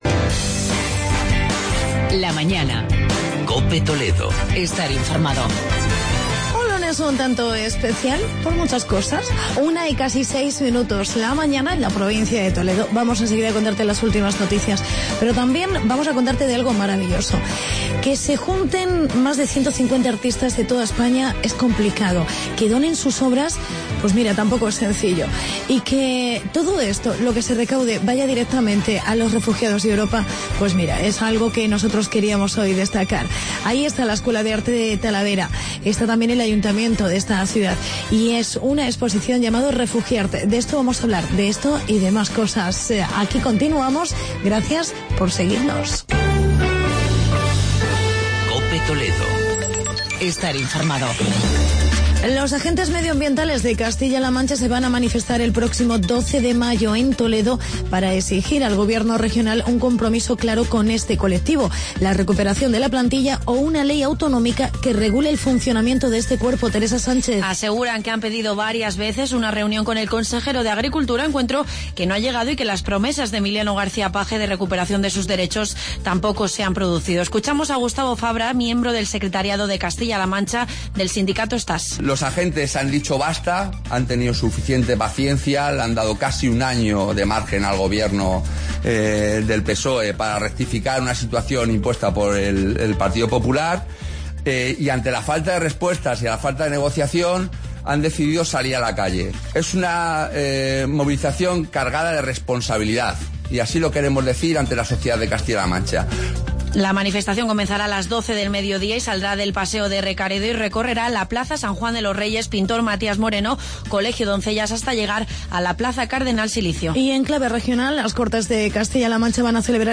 Entrevista
Reportaje sobre dolor de espalda